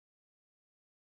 贡献 ） 协议：Copyright，其他分类： 分类:黄金船语音 您不可以覆盖此文件。